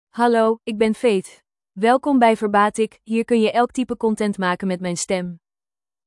Faith — Female Dutch AI voice
Faith is a female AI voice for Dutch (Netherlands).
Voice sample
Listen to Faith's female Dutch voice.
Female
Faith delivers clear pronunciation with authentic Netherlands Dutch intonation, making your content sound professionally produced.